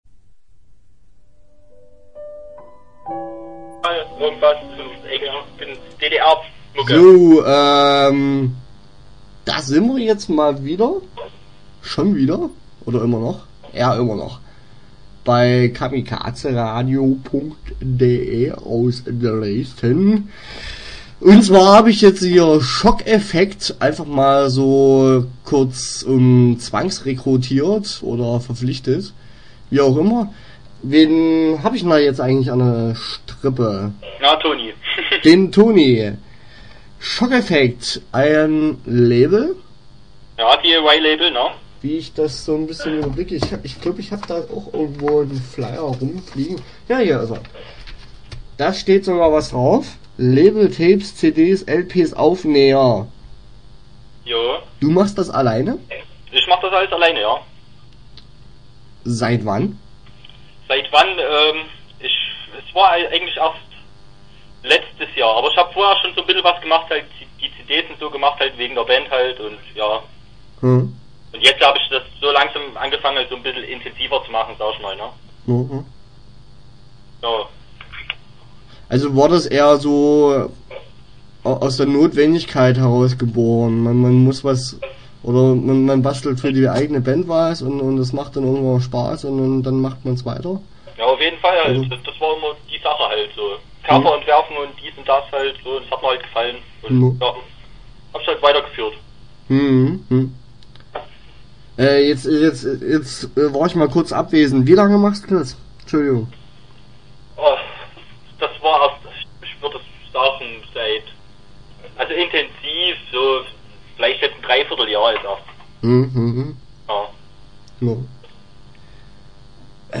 Interview Teil 1 (11:38)